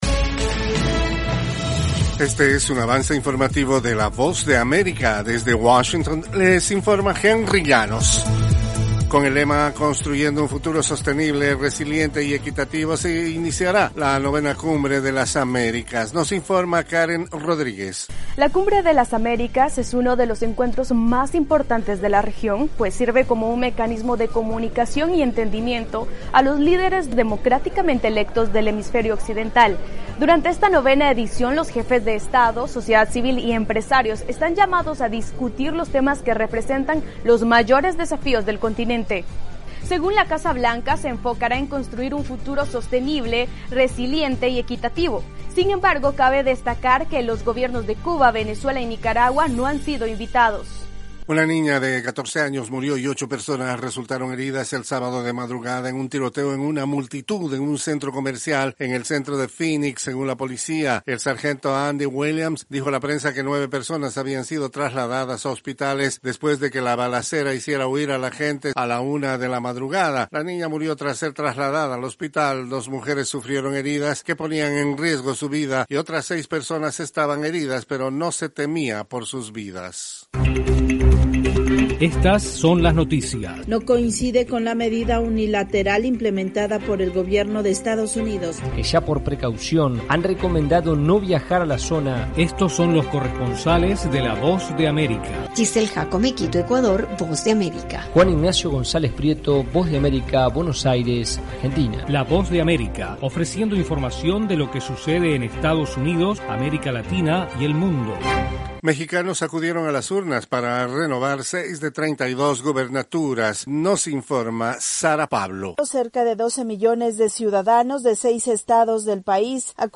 Desde los estudios de la Voz de América en Washington